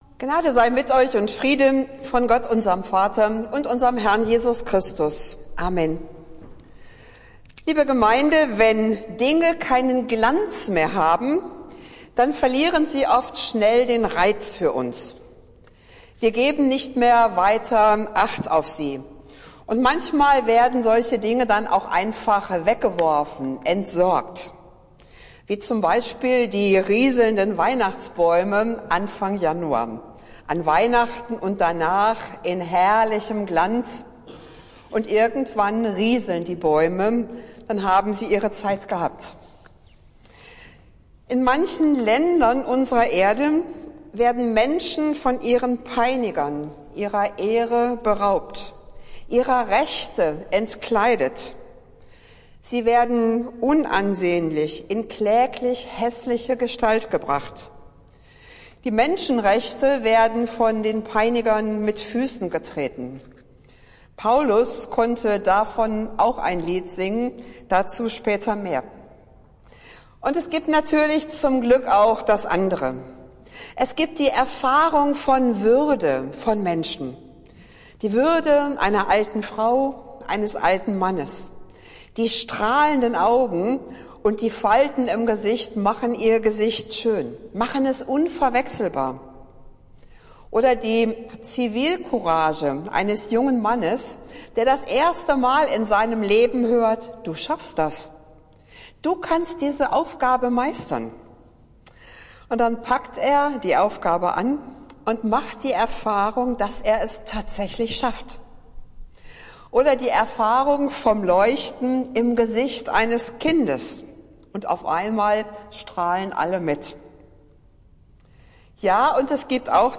Predigt des Gottesdienstes aus der Zionskirche vom Sonntag, den 28. Januar 2024